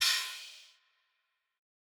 Crashes & Cymbals
DMV3_Crash 3.wav